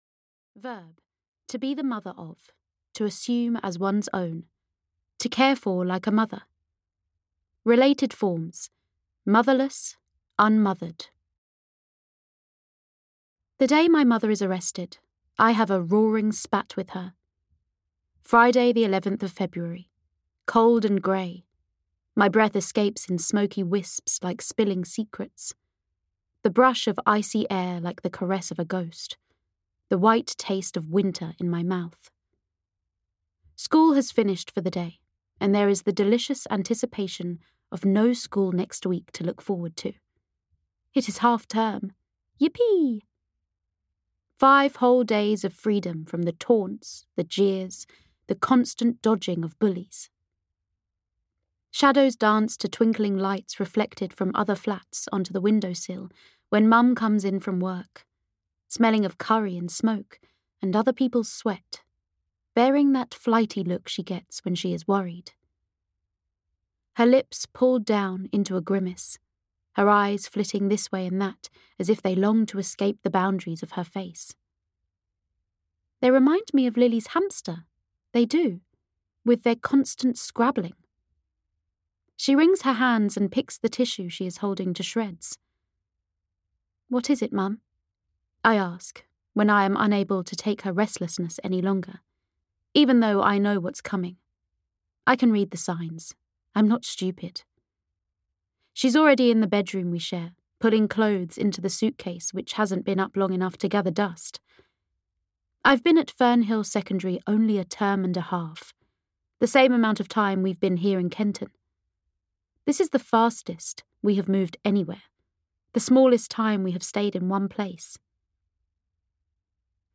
The Stolen Girl (EN) audiokniha
Ukázka z knihy